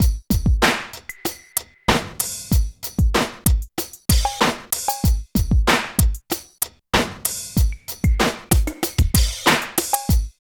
35 LOOP   -R.wav